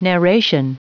Prononciation du mot narration en anglais (fichier audio)
Prononciation du mot : narration